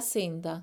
Guarda qui, anche se a me non sembra né un’affricata (canepariamente, un’occlu-costrittiva) dentale né un’approssimante [interd]entale bensí una semplice costrittiva alveolare.